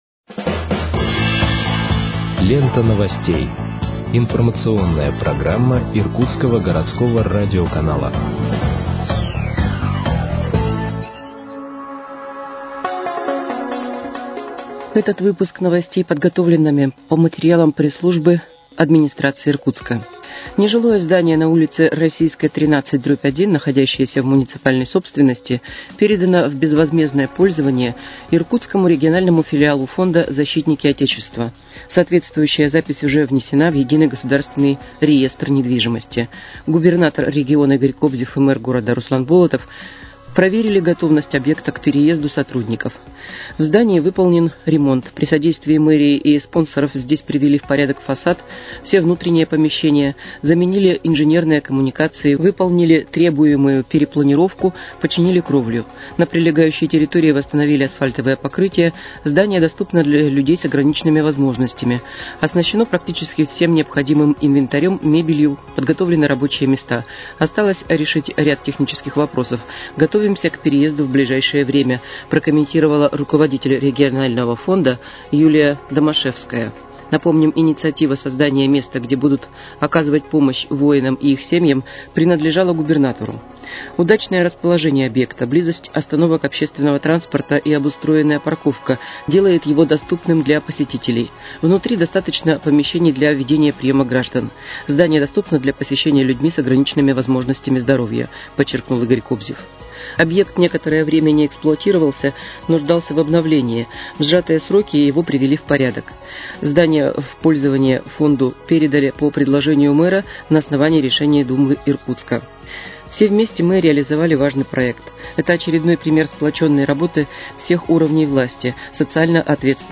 Выпуск новостей в подкастах газеты «Иркутск» от 07.10.2025 № 2